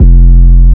REDD 808 (13).wav